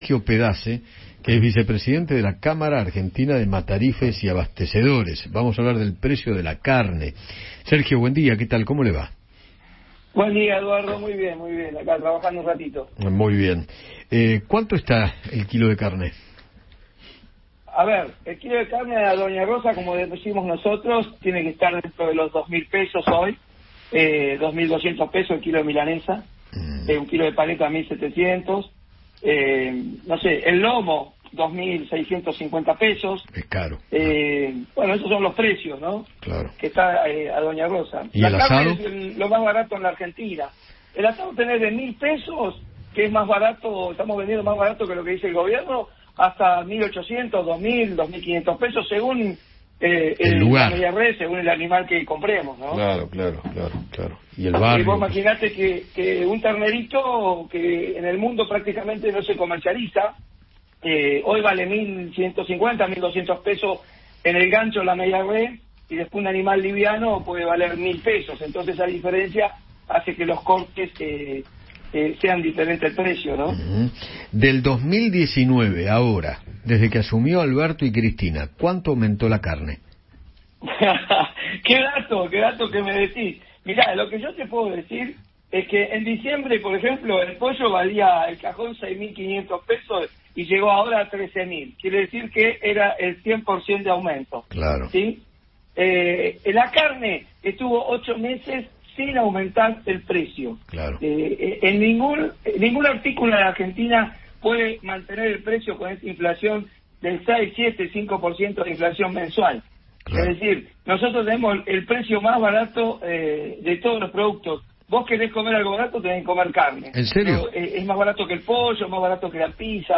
Eduardo Feinmann conversó con empresarios avícolas sobre el incremento del precio del pollo durante marzo.